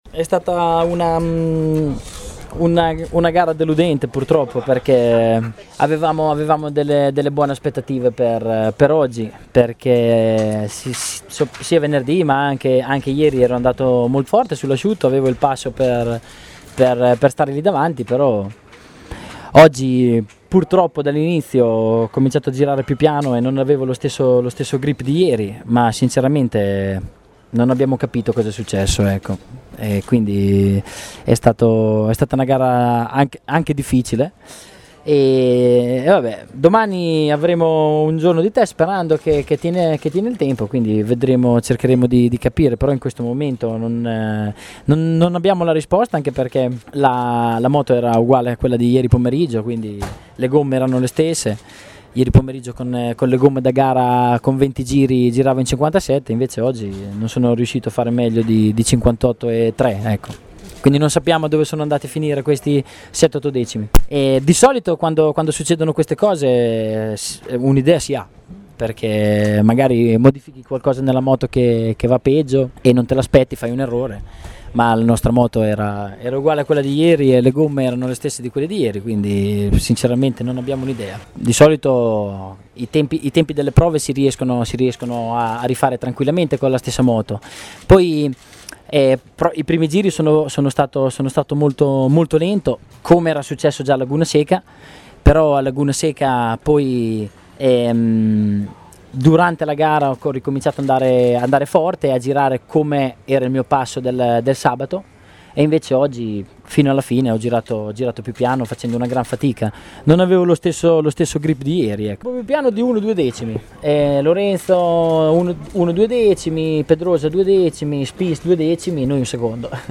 Questa e' l'intervista: